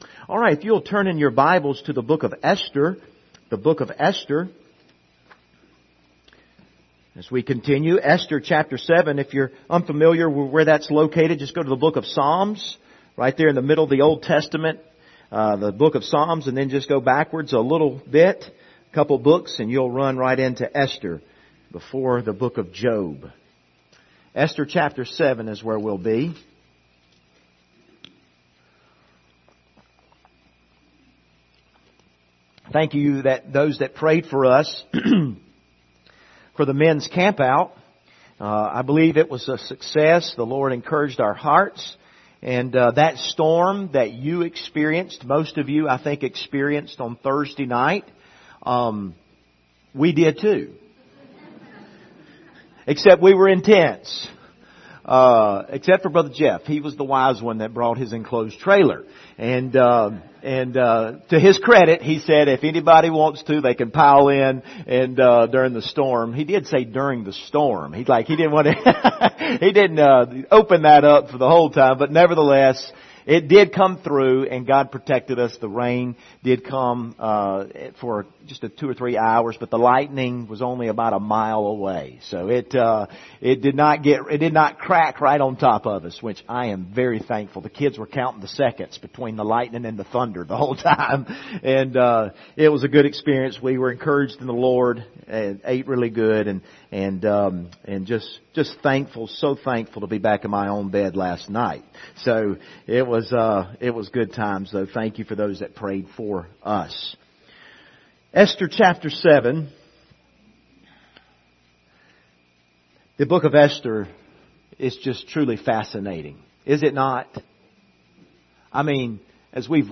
Passage: Esther 7 Service Type: Sunday Morning